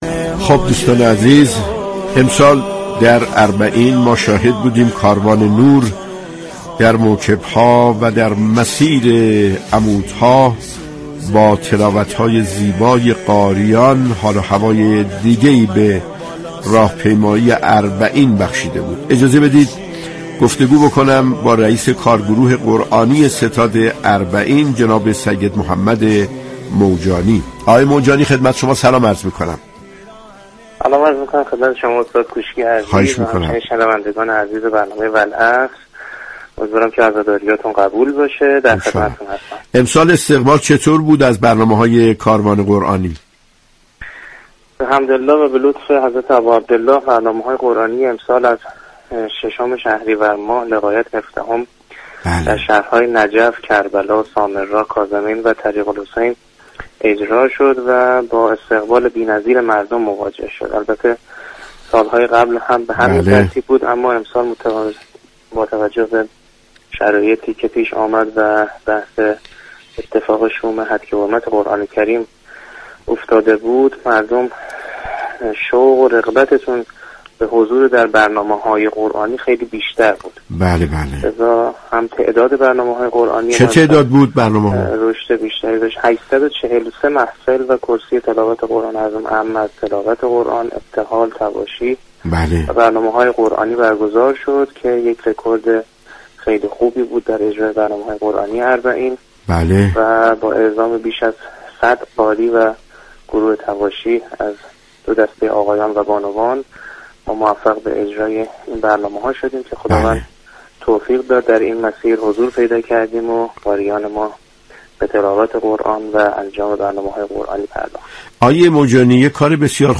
جنگ عصرگاهی "والعصر" با رویكرد اطلاع رسانی یكشنبه تا چهارشنبه ی هر هفته بصورت زنده از رادیو قرآن پخش می شود.